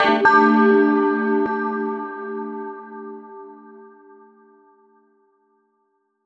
Chords_A_03.wav